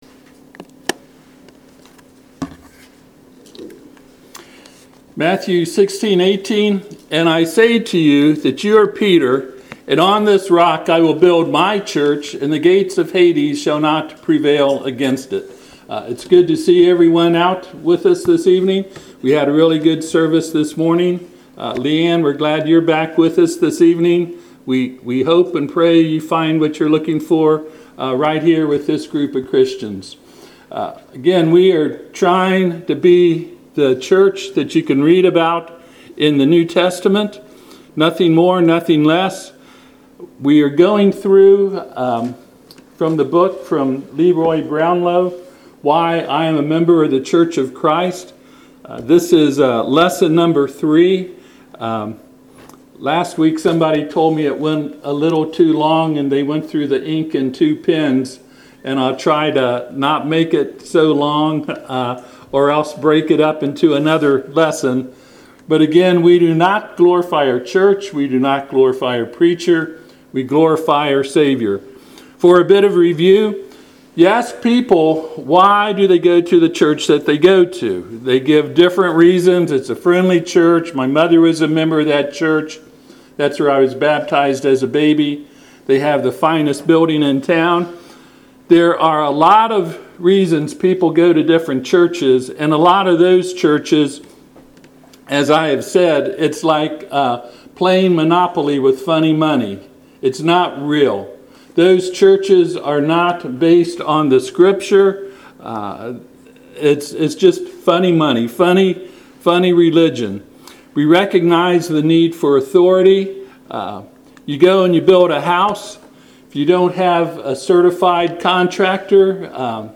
Passage: Matthew 15:16-20 Service Type: Sunday PM